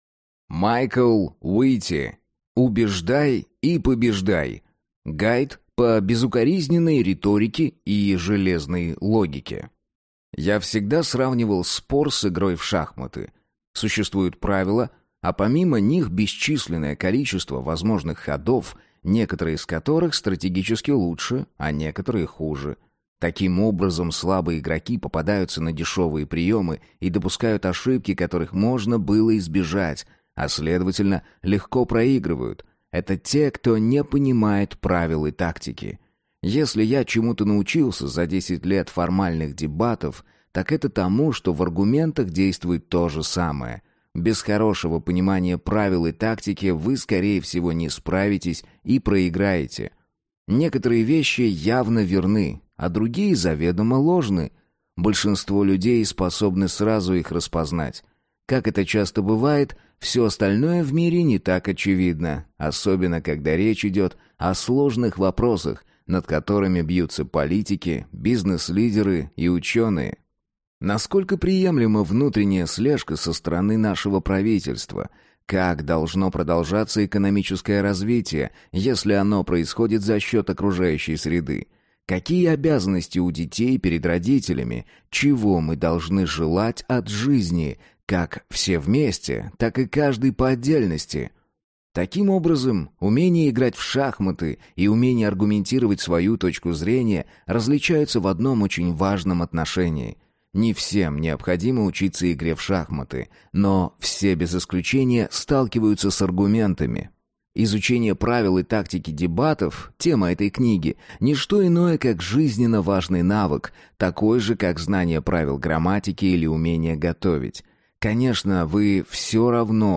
Аудиокнига Убеждай и побеждай! Гайд по безукоризненной риторике и железной логике | Библиотека аудиокниг